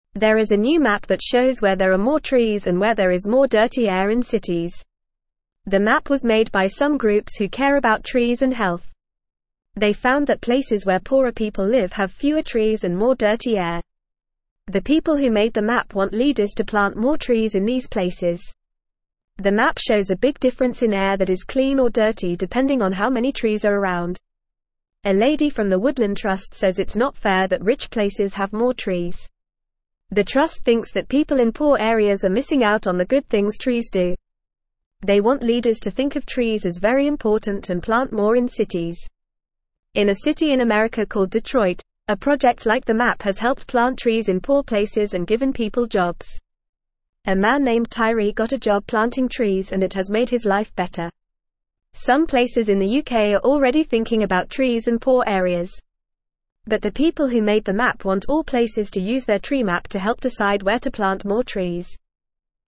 Fast
English-Newsroom-Lower-Intermediate-FAST-Reading-Map-Shows-Places-with-Few-Trees-Have-Dirty-Air.mp3